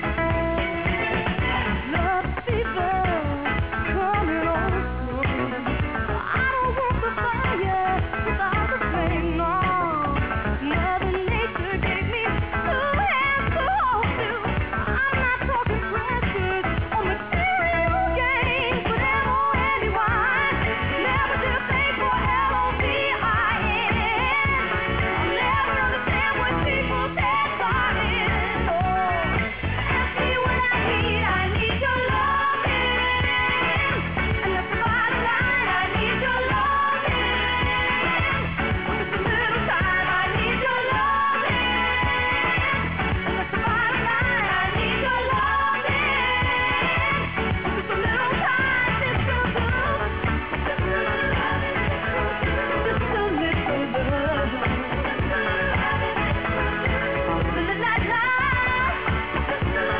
70's/DISCO